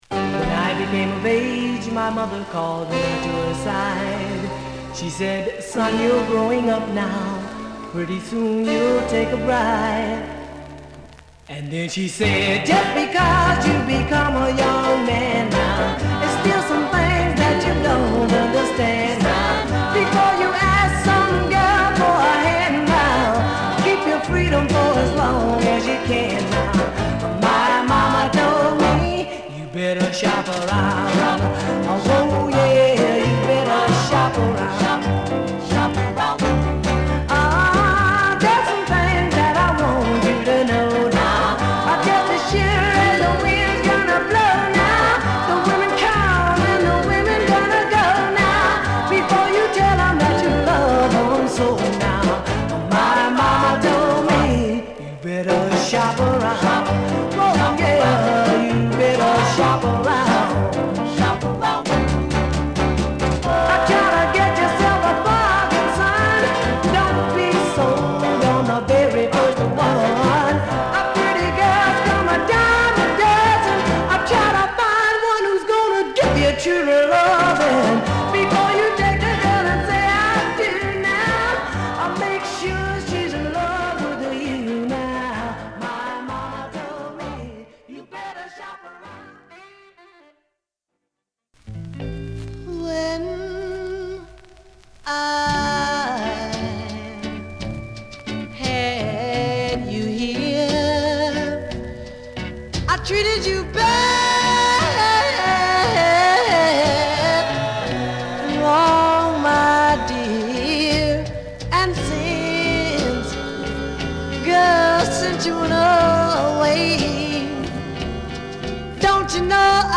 弾んだリズムのポップなR&Bチューン！
針飛びしませんが数回ポツッと鳴ります。